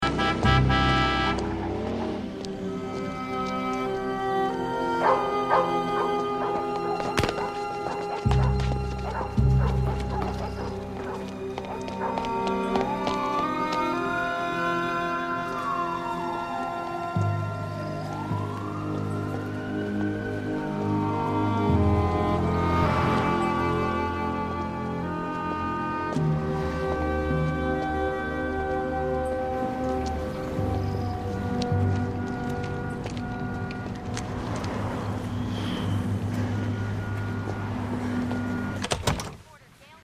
Musique issue de l’album: DVD rip